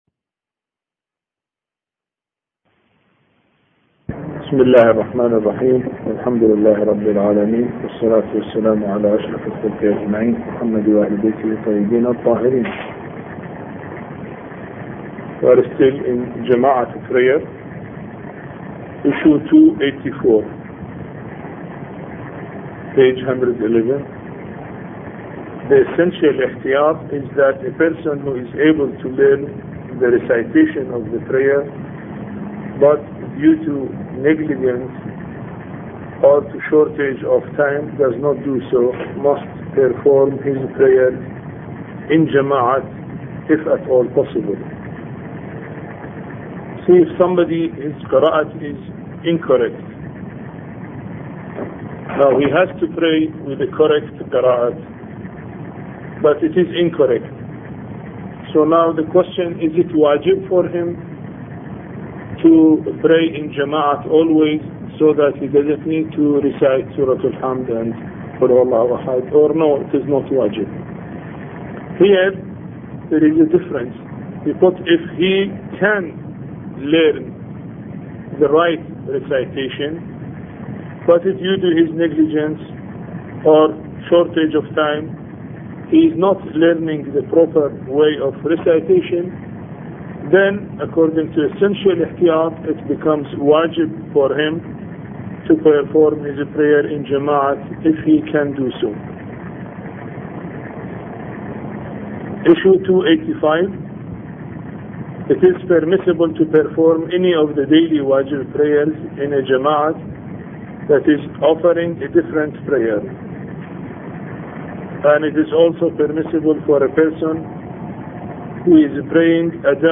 A Course on Fiqh Lecture 15